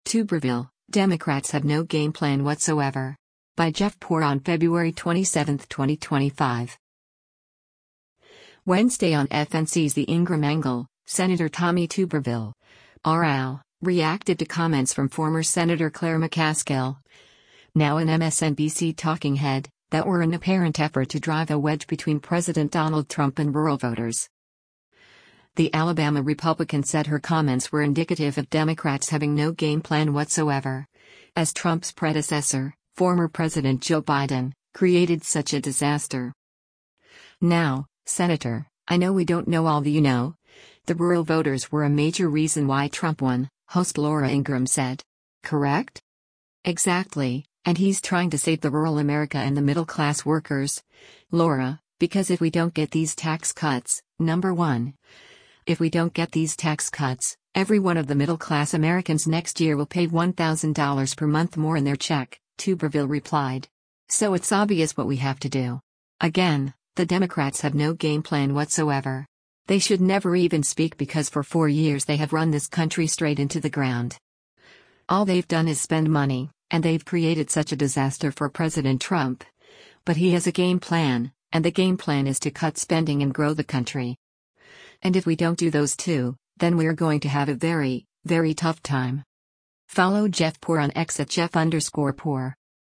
Wednesday on FNC’s “The Ingraham Angle,” Sen. Tommy Tuberville (R-AL) reacted to comments from former Sen. Claire McCaskill, now an MSNBC talking head, that were an apparent effort to drive a wedge between President Donald Trump and rural voters.